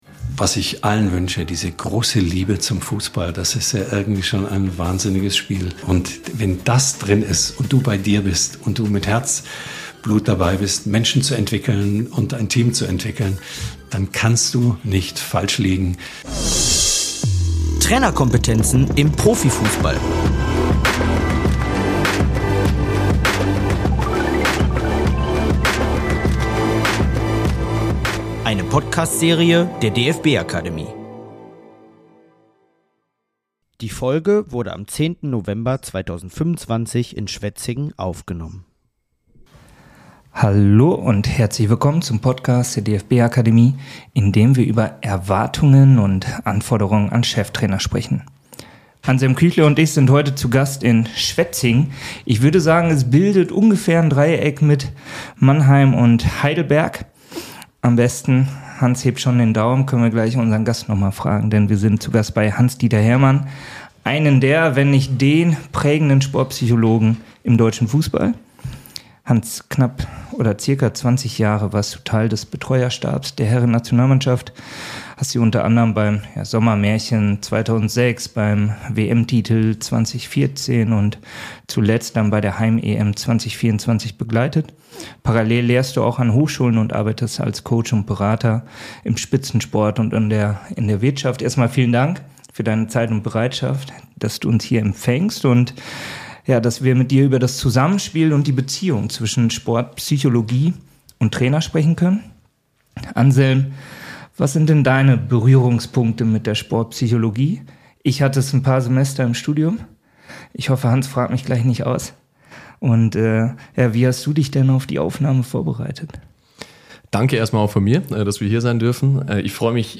Trotz aller Technik bleibt der Kern gleich: Nachhaltiger Erfolg entsteht durch authentische Führung, menschliche Nähe und die Fähigkeit, Menschen zu entwickeln. Die Podcast-Folge wurde am 10. November 2025 in Schwetzingen aufgenommen.